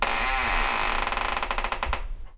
squeaky-door-open-2.ogg